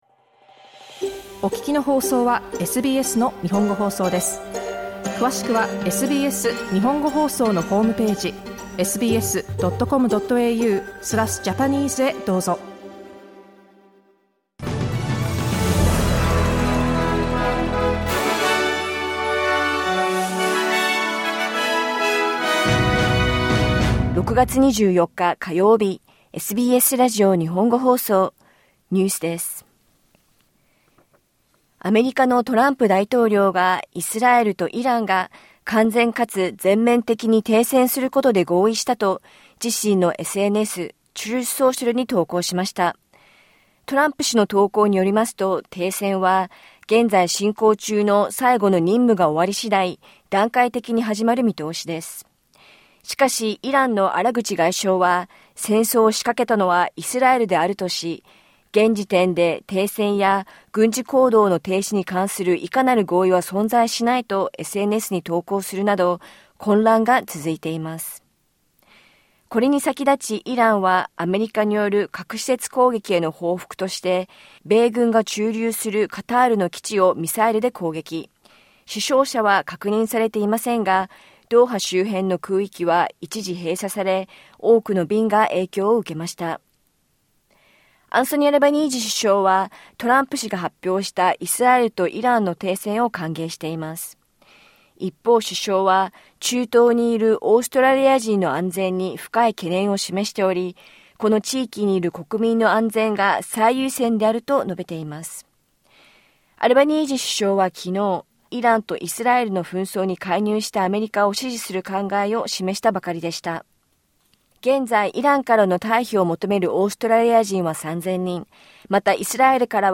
オーストラリアではおよそ330万人が貧困ライン以下で暮らしていることが新たな調査でわかりました。午後１時から放送されたラジオ番組のニュース部分をお届けします。